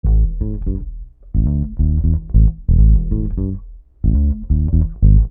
Bass 04.wav